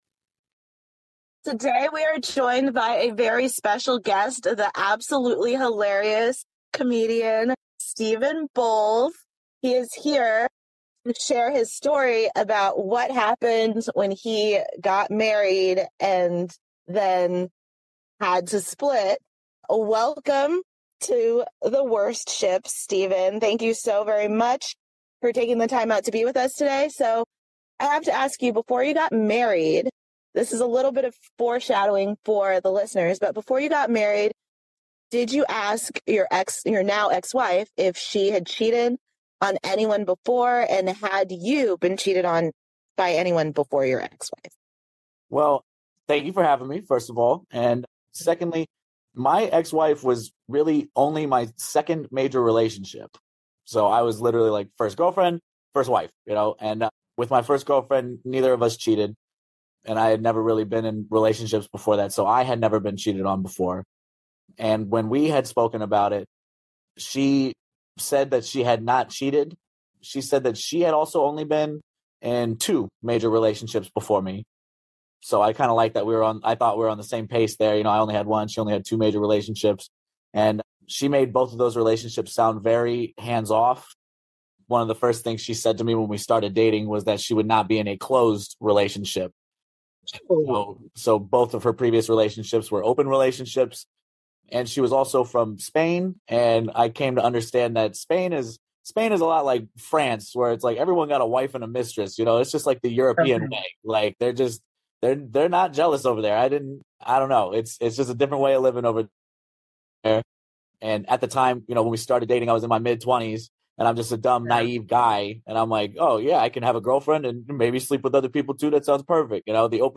Interview 1